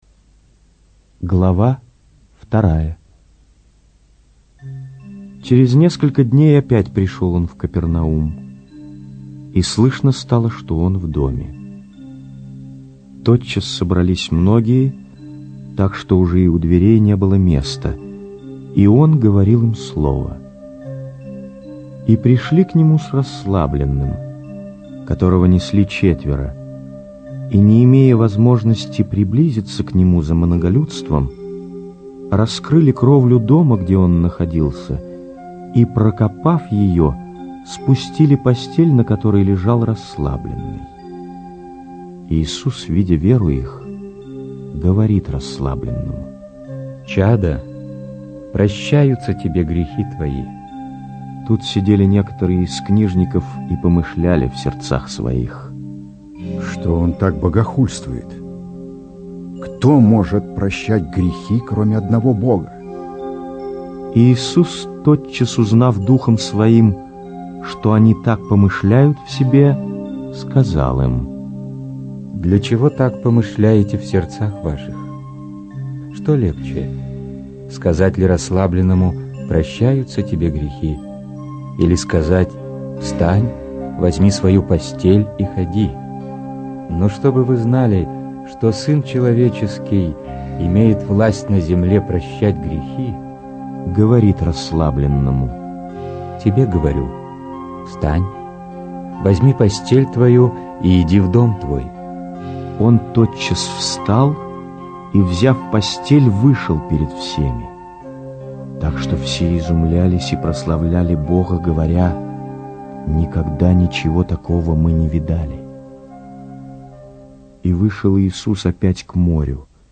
инсценированная аудиозапись    Подробнее...